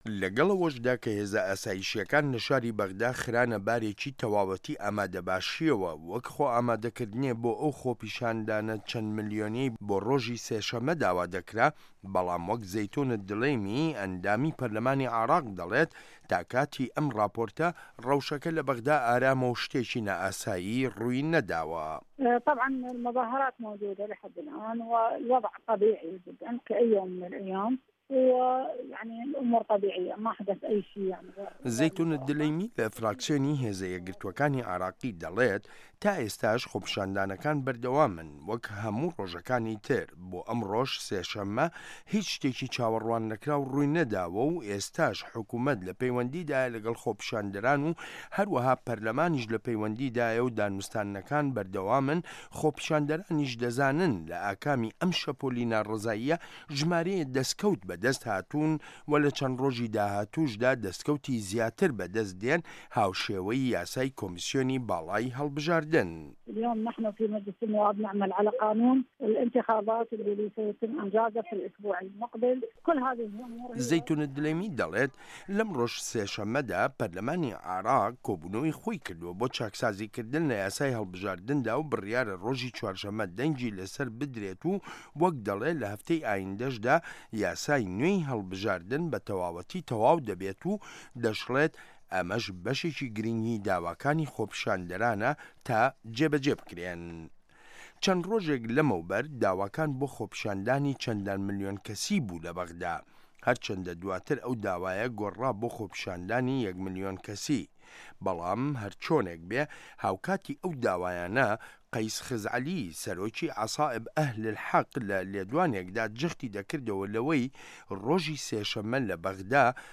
عێراق - گفتوگۆکان
ڕاپۆرت لەسەر بنچینەی لێدوانەکانی زەیتون ئەلدولەیمی